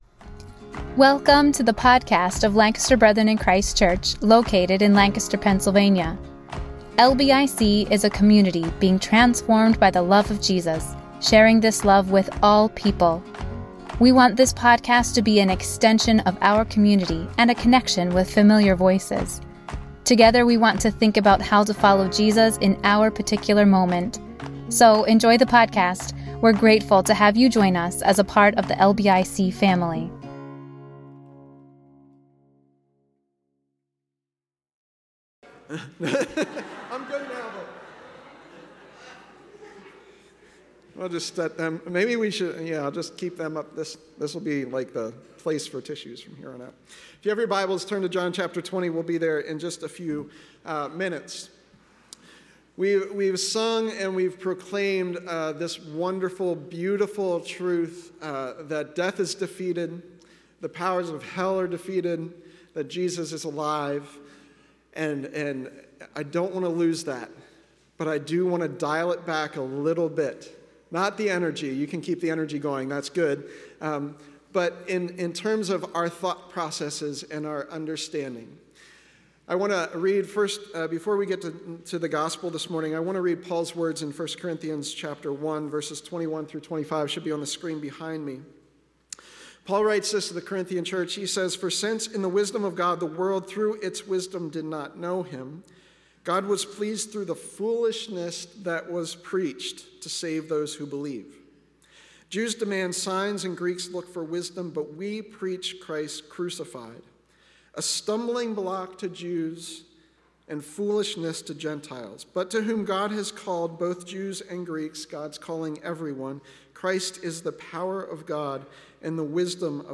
A message from the series "Eastertide."